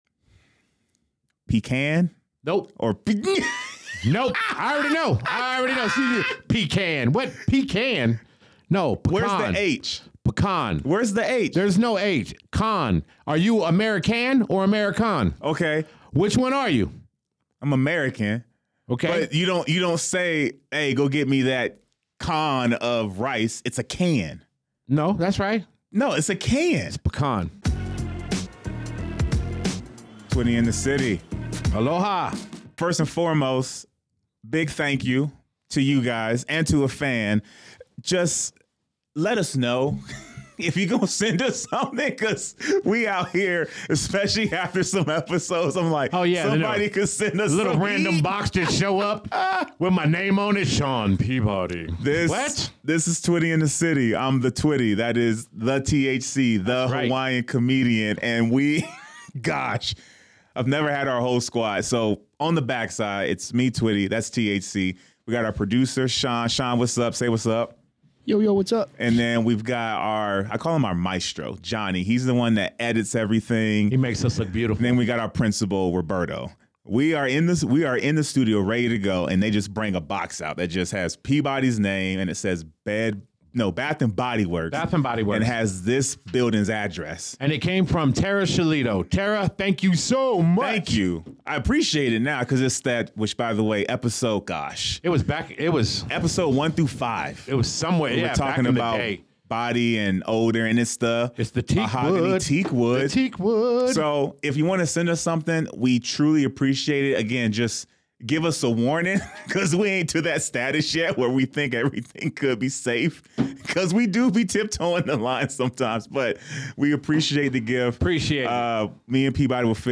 Perfect for American English pronunciation, accent differences, and U.S. regional dialect breakdowns.